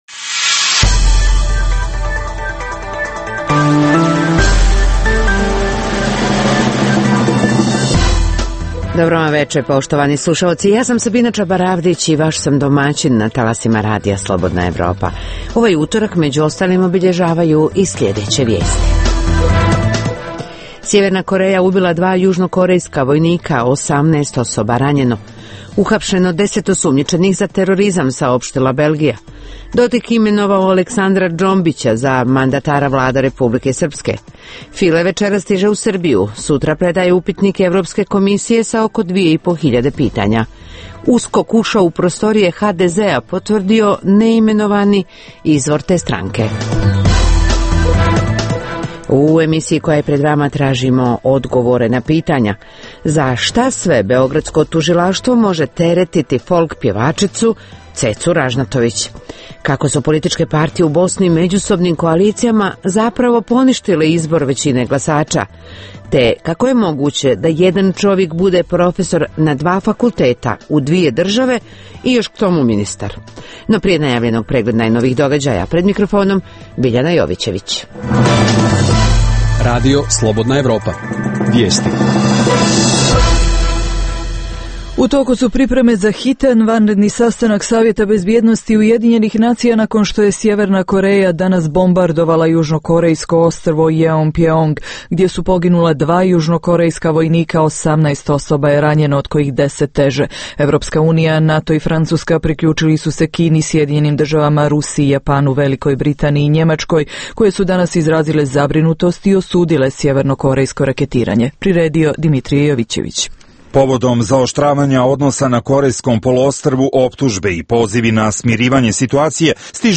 Prvih pola sata emisije sadrži regionalne i vijesti iz svijeta, te najaktuelnije i najzanimljivije teme o dešavanjima u zemljama regiona i teme iz svijeta. Preostalih pola sata emisije, nazvanih “Dokumenti dana” sadrži analitičke teme, intervjue i priče iz života.